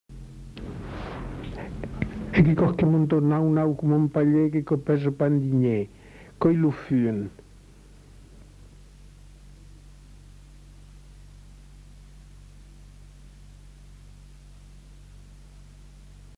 Aire culturelle : Haut-Agenais
Genre : forme brève
Type de voix : voix d'homme
Production du son : récité
Classification : devinette-énigme